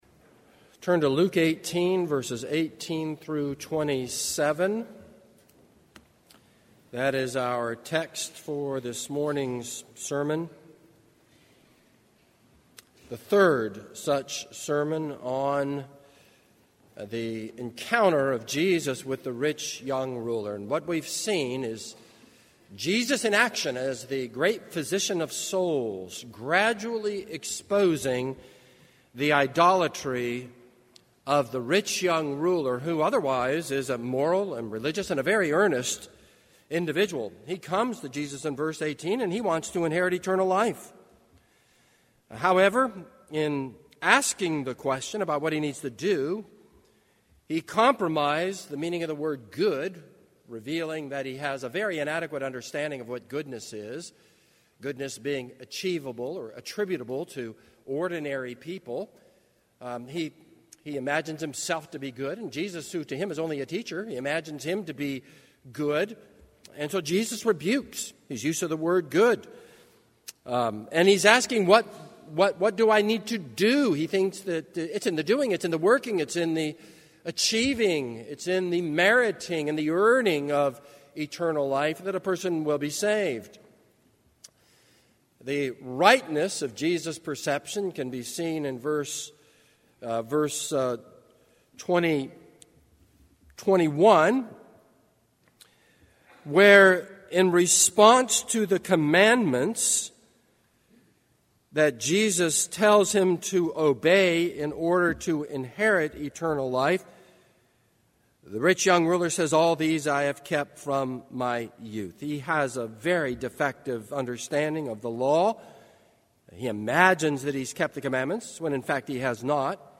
This is a sermon on Luke 18:18-27.